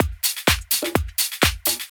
• tuned washed house drum loop.wav
tuned_washed_house_drum_loop_ZMe.wav